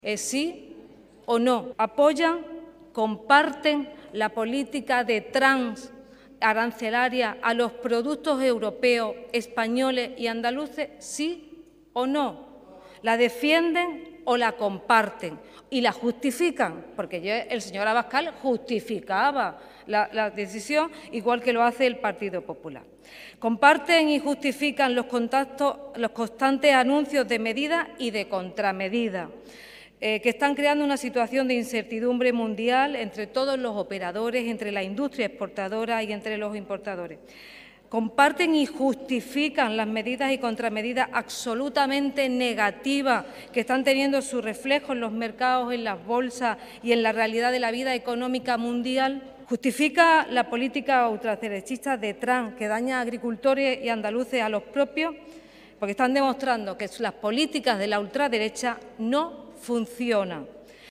Así ha concluido su intervención la portavoz del Grupo Socialista, María Márquez, en la sesión del control al Gobierno, en la que ha avisado al presidente de la Junta que la Justicia está llamando a su puerta, ya que por primera vez investiga “los papeles que lleva su firma”.